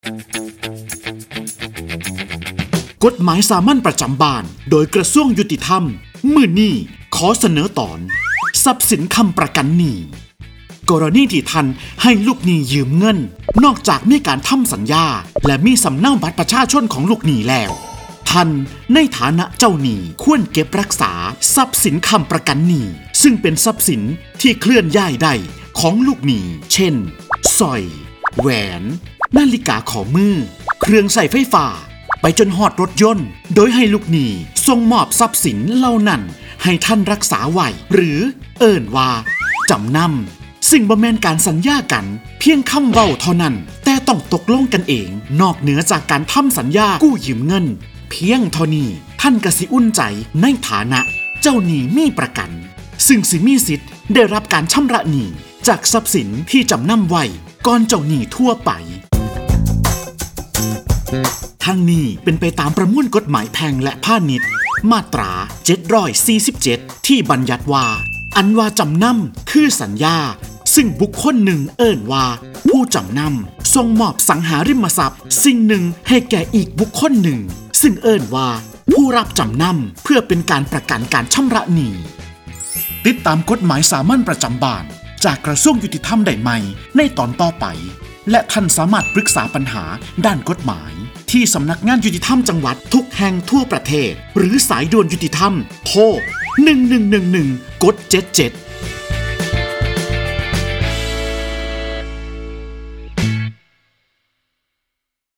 กฎหมายสามัญประจำบ้าน ฉบับภาษาท้องถิ่น ภาคอีสาน ตอนทรัพย์สินค้ำประกันหนี้
ลักษณะของสื่อ :   บรรยาย, คลิปเสียง